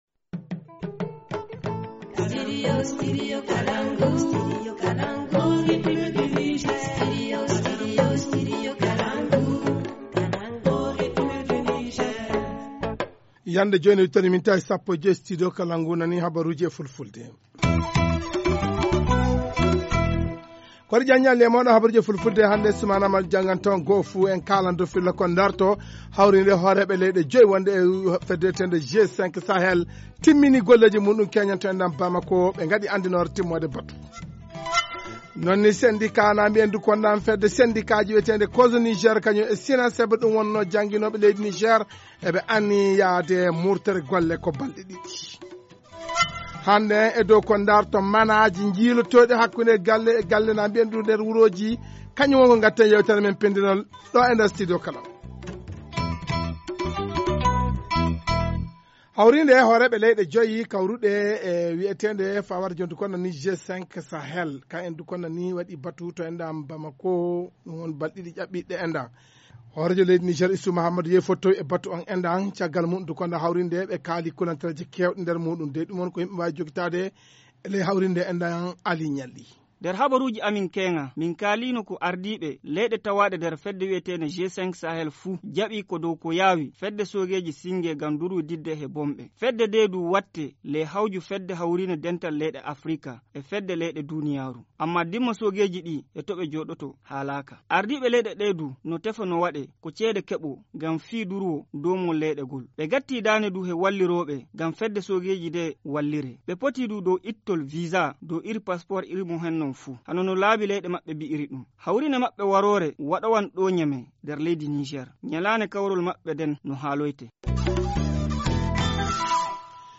Vous entendrez dans ce journal le Co-coordonnateur de la coalition sur les motifs de ce débrayage et les réaction d’un parents d’élève sur cette situation.